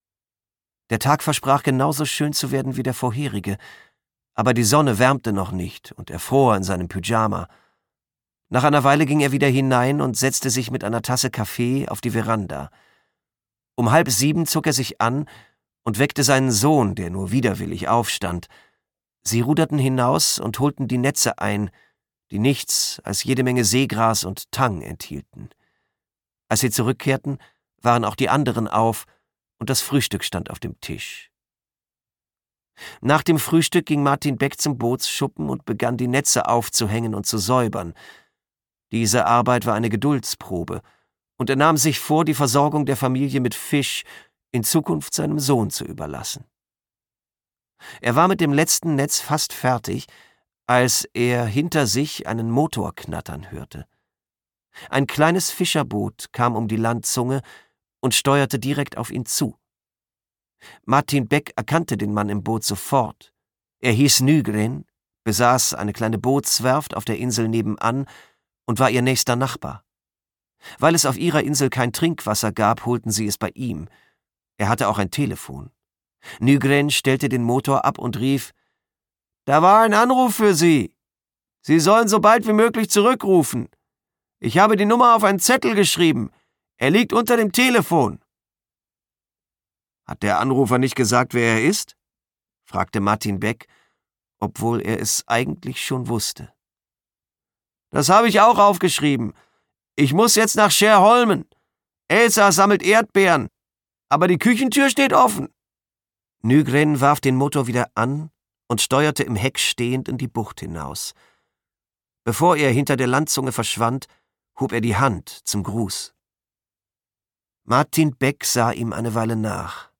Schweden-Krimi
Gekürzt Autorisierte, d.h. von Autor:innen und / oder Verlagen freigegebene, bearbeitete Fassung.